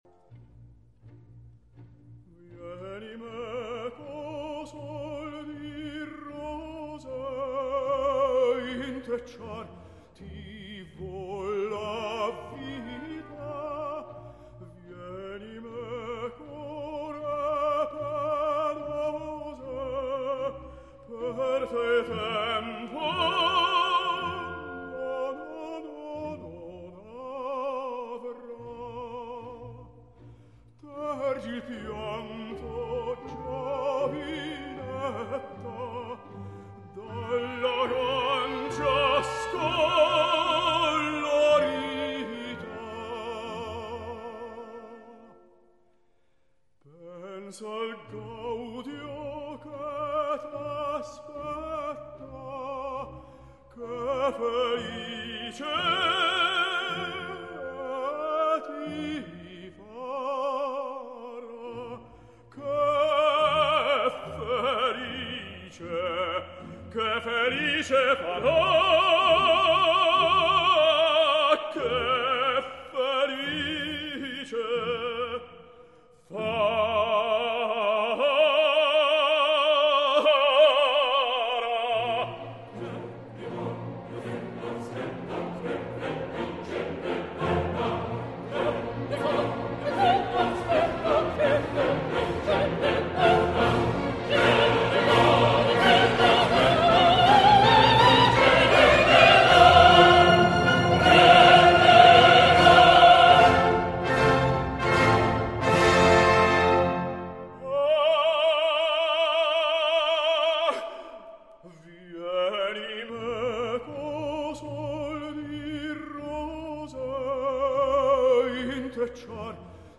Carlo, Silva & Elvira